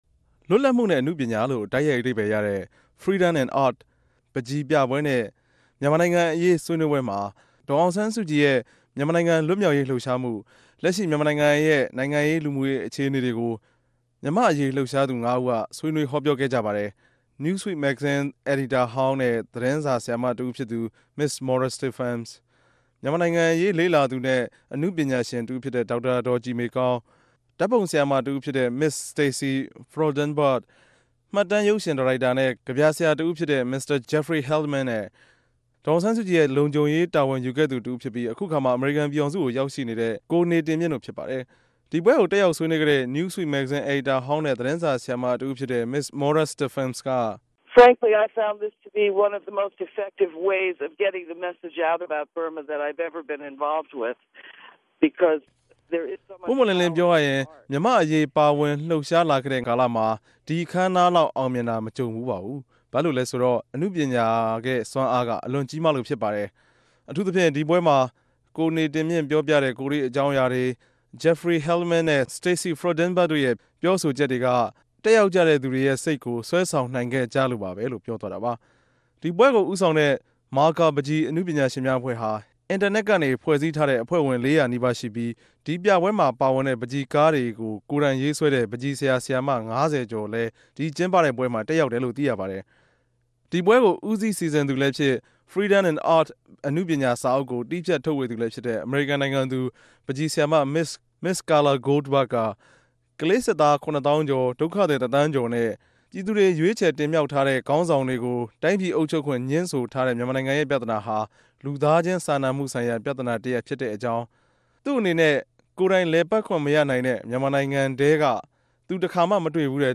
ဒီူပပြဲမြာ ဦးဆောင် ဆြေးေိံြးခဲ့သူတေနြဲႛ ဆက်သြယ်မေးူမန်္ဘးပီး စုစည်းတင်ူပထားတာကို အူပည့်အစုံ နားဆငိံိုင်ပၝတယ်။
ဆက်သြယ်မေးူမန်းခဵက်။